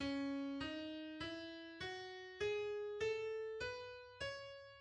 • Natuurlijke mineurtoonladder: C♯ - D♯ - E - F♯ - G♯ - A - B - C♯